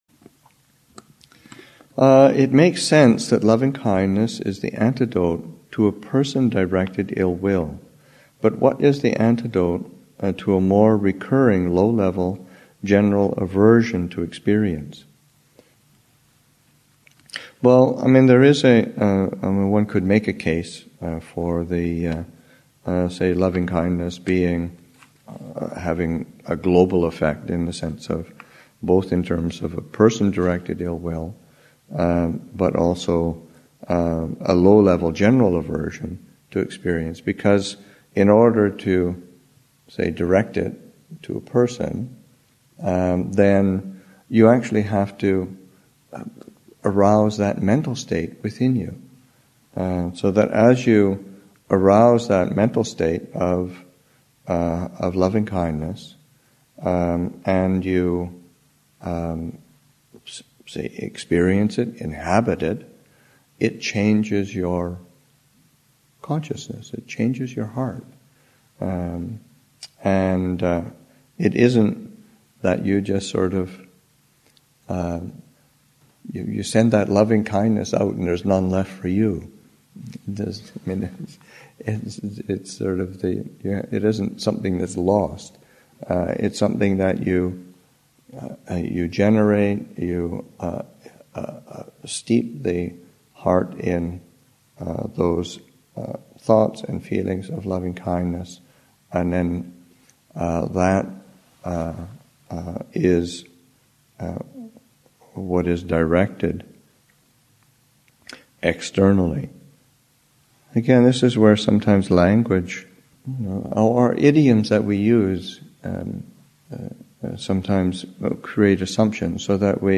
Abhayagiri Monastic Retreat 2013, Session 6 – Nov. 28, 2013